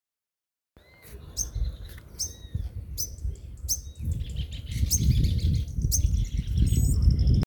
Cachilo Corona Castaña (Rhynchospiza strigiceps)
Nombre en inglés: Chaco Sparrow
Localidad o área protegida: Potrero de Garay
Condición: Silvestre
Certeza: Fotografiada, Vocalización Grabada